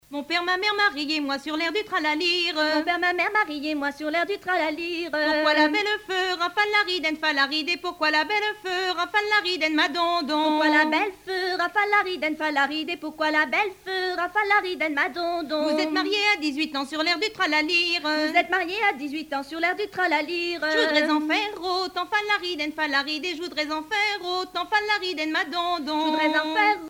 danse : ronde
Pièce musicale éditée